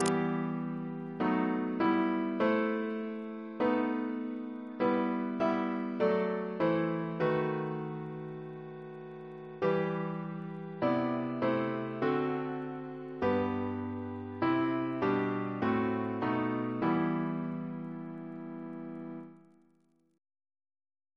Double chant in E minor Composer: Sir John Stainer (1840-1901), Organist of St. Paul's Cathedral Reference psalters: ACP: 111; CWP: 4; RSCM: 126